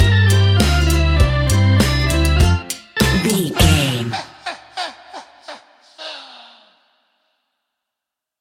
In-crescendo
Aeolian/Minor
tension
ominous
haunting
eerie
horror music
Horror Pads
horror piano
Horror Synths